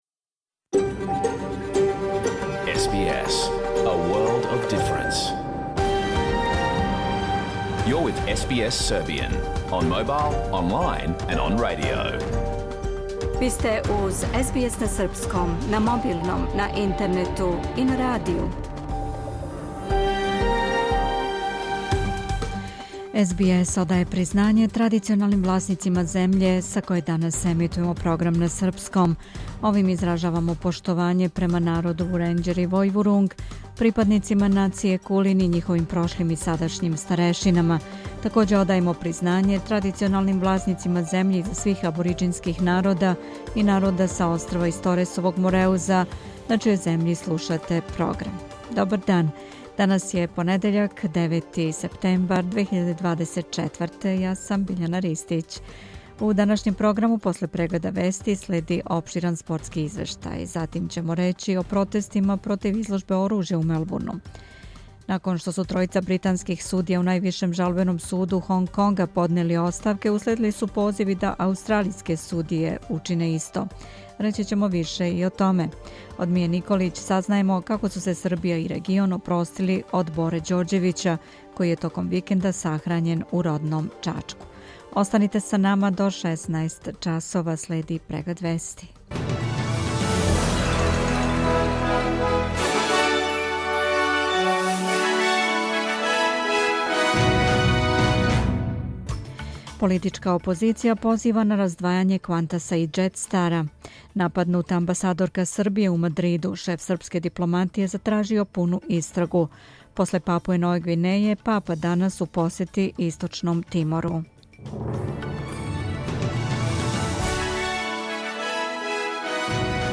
Програм емитован уживо 9. септембра 2024. године
Уколико сте пропустили данашњу емисију, можете је послушати у целини као подкаст, без реклама.